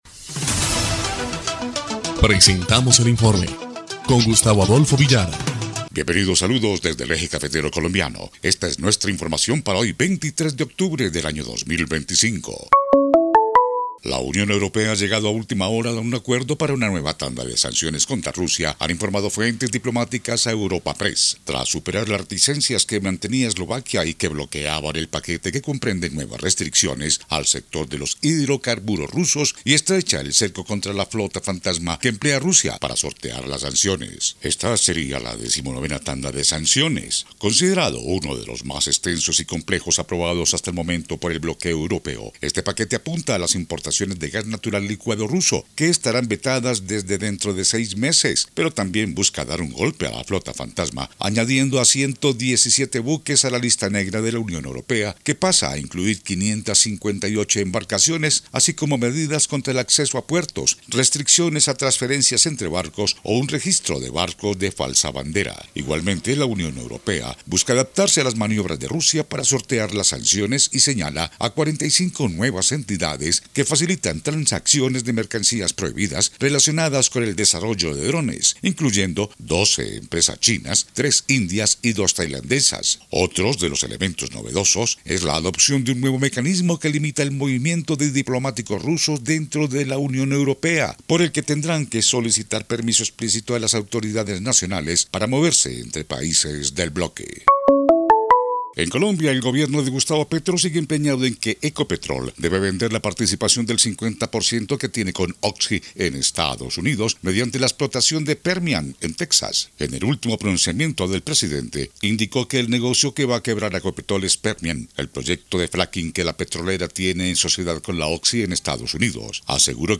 EL INFORME 1° Clip de Noticias del 23 de octubre de 2025